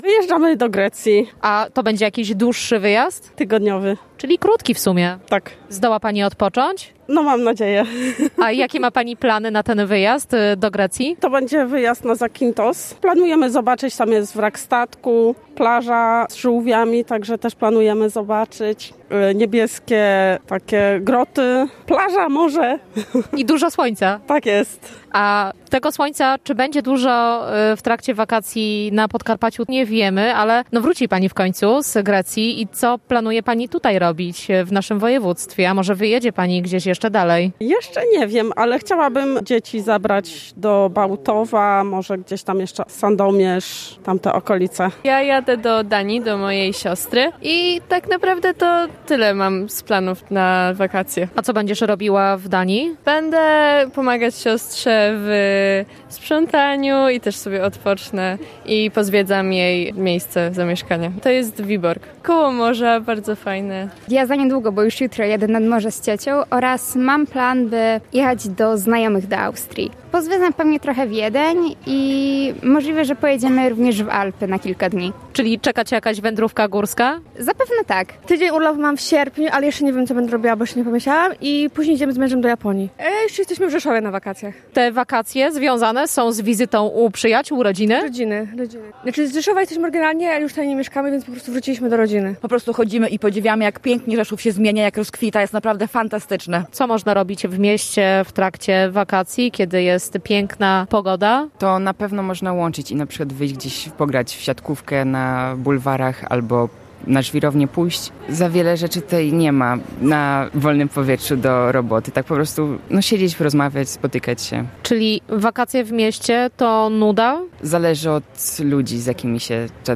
Zapytaliśmy przechodniów w Rzeszowie o wakacyjne plany. Jak się okazuje, w tym roku nie zabraknie zagranicznych wyjazdów.